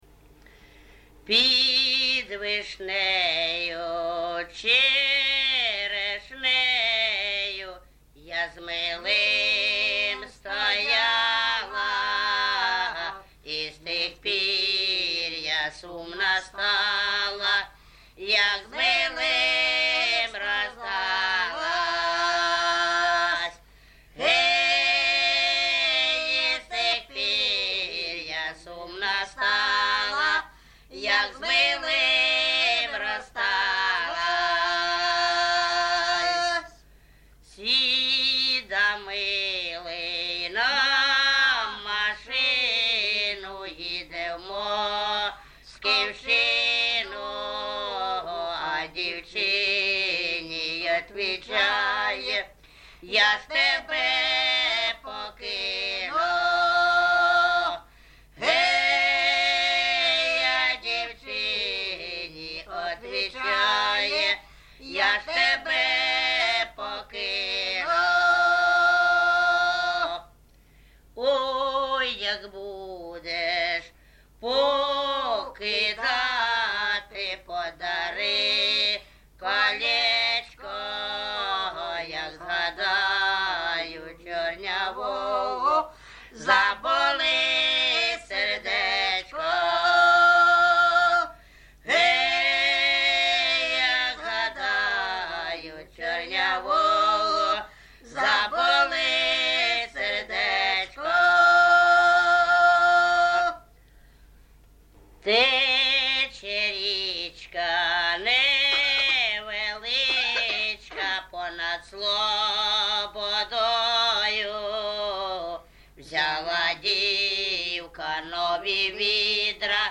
ЖанрПісні з особистого та родинного життя, Балади
Місце записус. Гарбузівка, Сумський район, Сумська обл., Україна, Слобожанщина